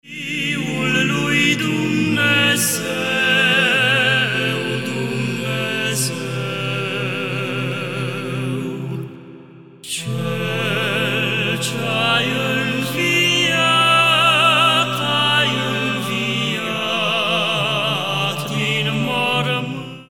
four male voices
Byzantine Orthodox Songs